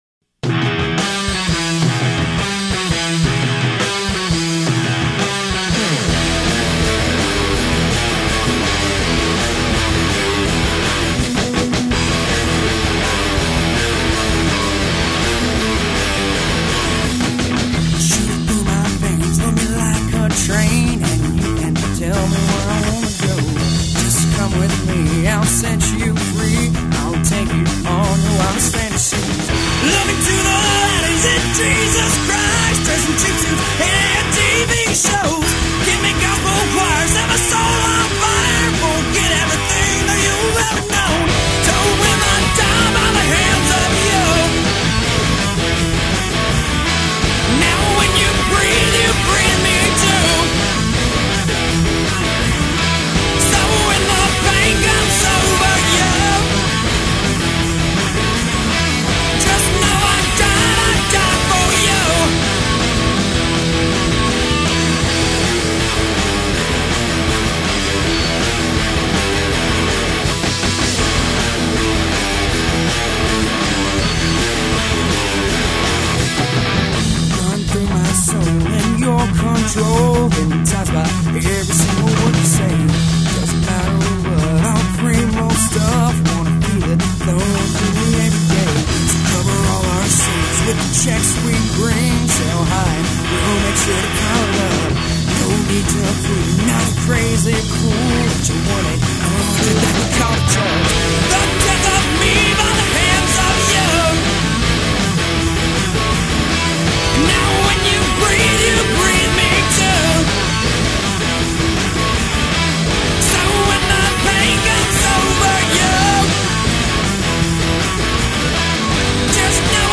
Type of music: Rock, Alternative